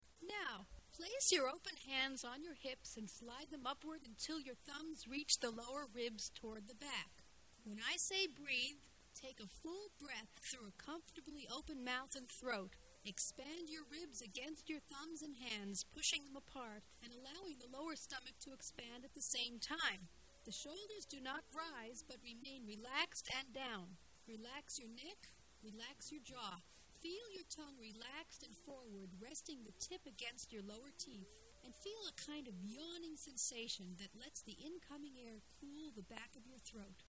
Listen to how they explain and demonstrate breathing technique.